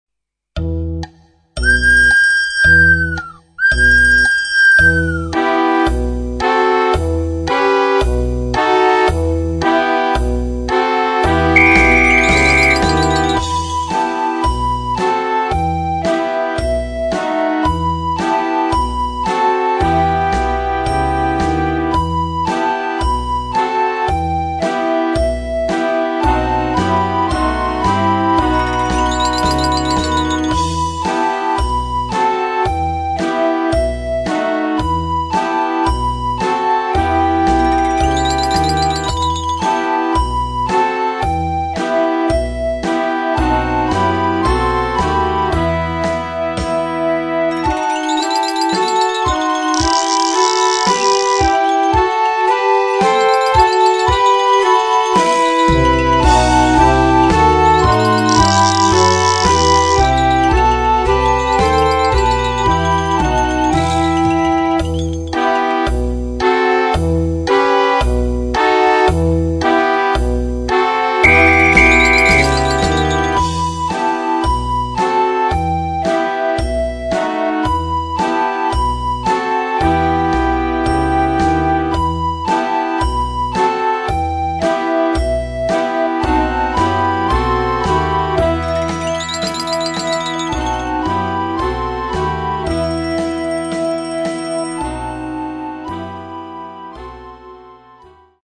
Groupe de flûtes à bec avec accompagnement orchestral
Partitions pour ensemble flexible, 4-voix + percussion.